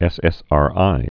(ĕsĕs-är-ī)